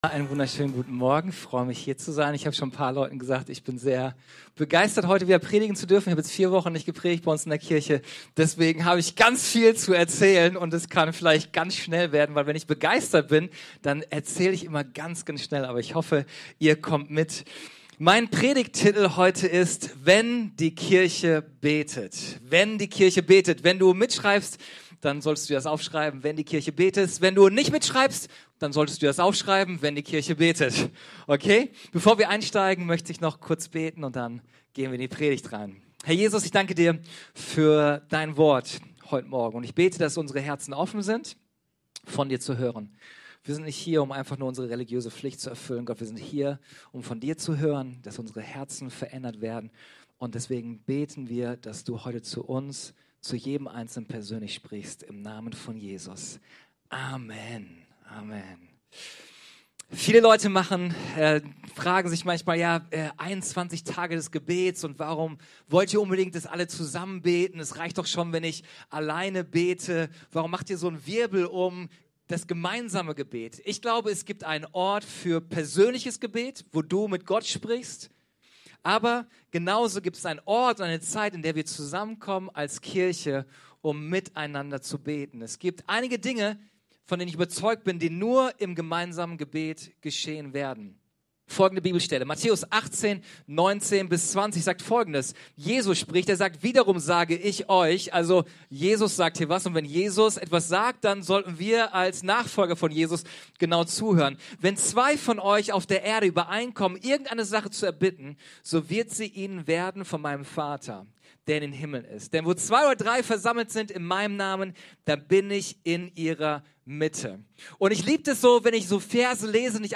Gottesdienst vom 04.08.24 Folge direkt herunterladen